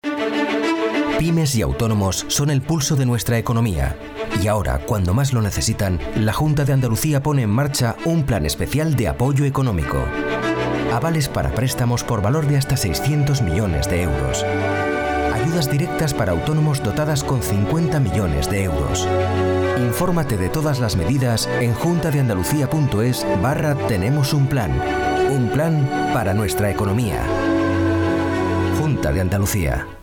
Annonces politiques
Bonjour, je suis une voix off espagnole européenne à temps plein depuis 1992.
Microphones : Sennheiser 416, Rode NT1, NT2-A
Baryton
jc-t-spanish-political-ads-demo.mp3